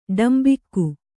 ♪ ḍambikku